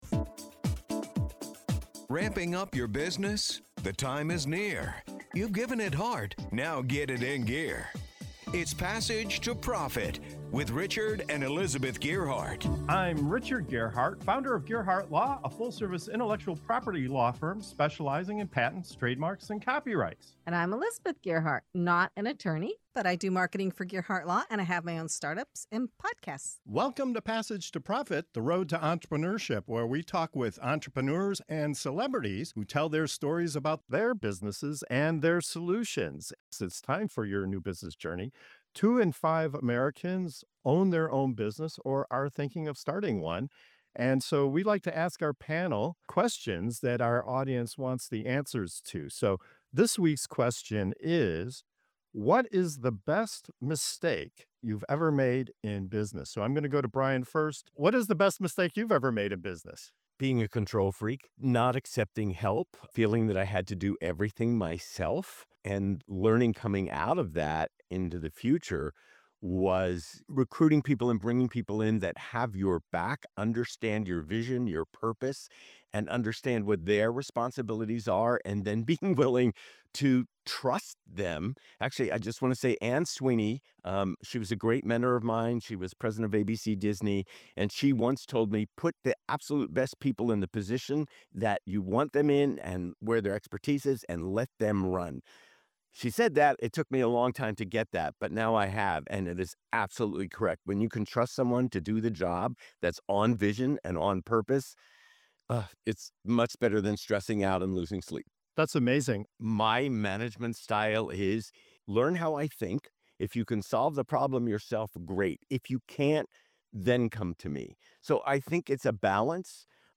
In this segment of “Your New Business Journey” on Passage to Profit Show, our panel of entrepreneurs shares the "best mistakes" they've made in business—missteps that ultimately led to growth, success, and unexpected opportunities. From learning to trust a team, to prioritizing health, to navigating legal hurdles, these stories reveal the hidden value of failure.